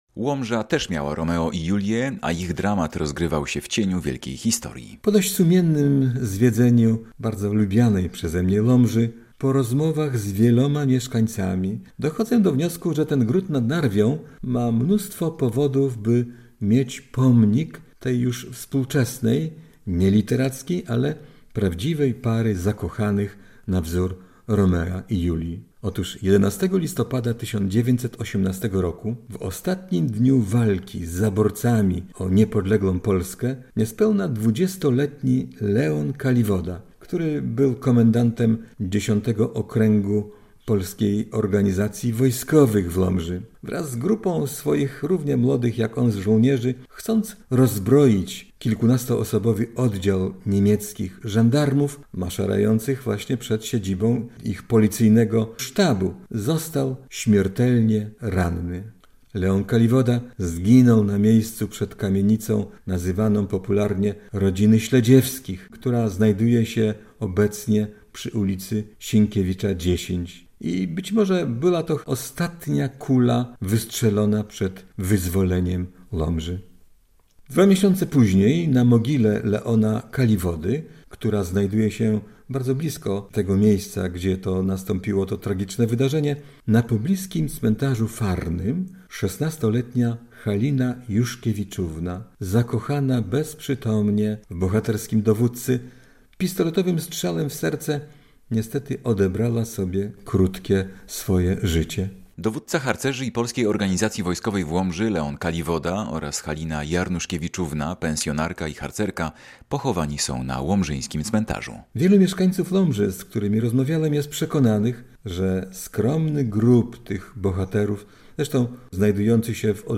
Prowadzący: